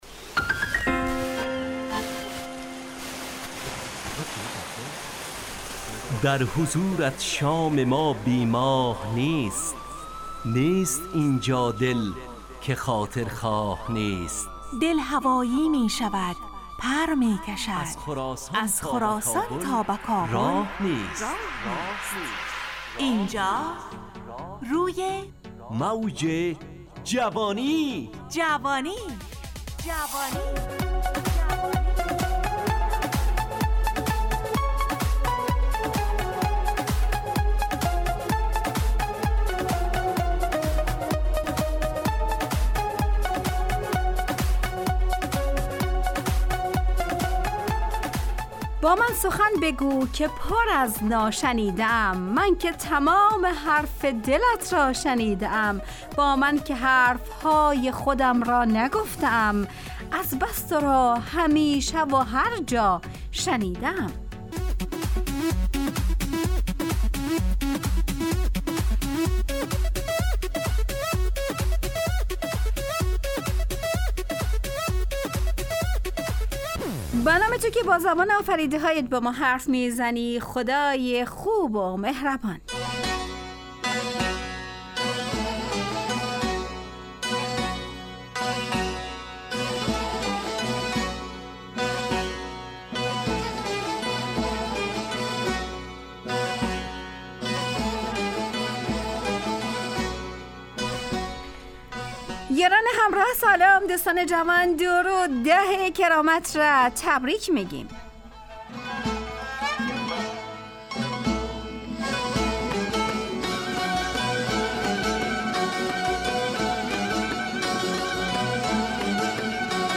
از شنبه تا پنجشنبه ازساعت 4:45 الی5:55 به وقت افغانستان، طرح موضوعات روز، وآگاهی دهی برای جوانان، و.....بخشهای روزانه جوان پسند. همراه با ترانه و موسیقی مدت برنامه 70 دقیقه .